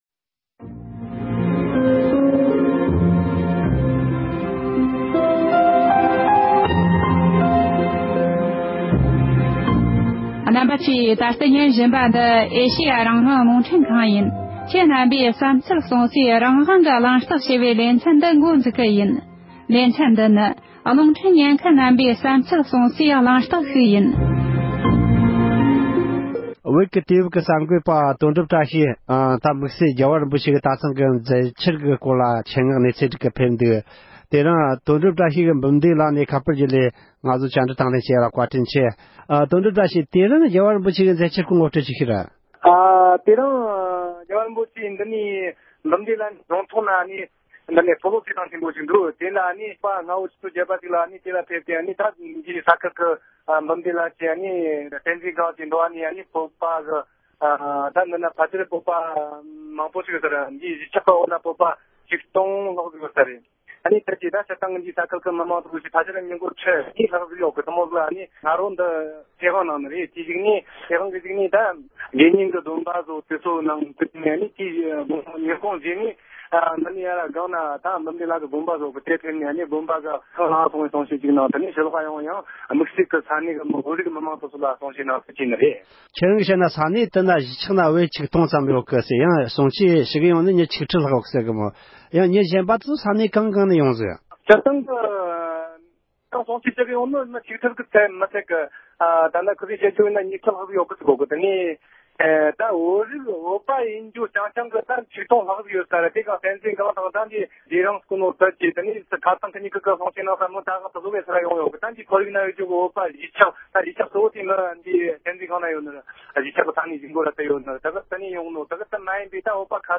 གླེང་མོལ་ཞུས་པ་ཞིག་ལ་གསན་རོགས༎